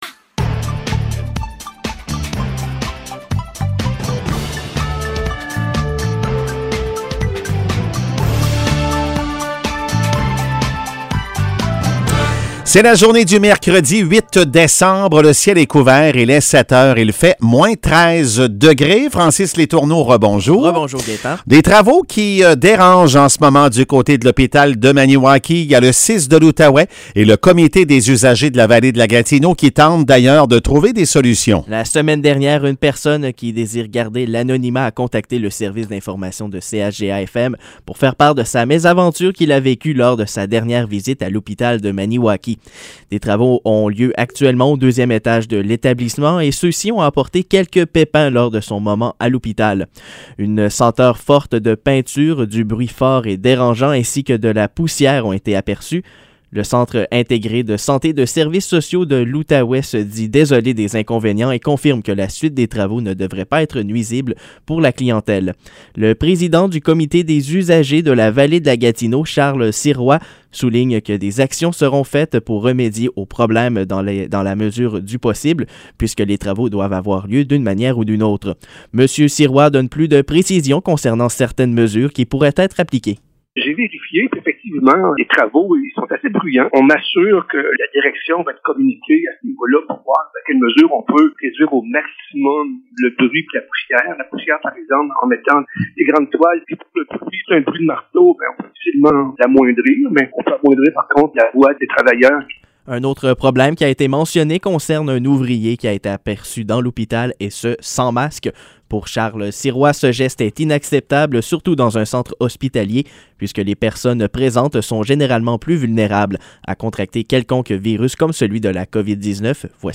Nouvelles locales - 8 décembre 2021 - 7 h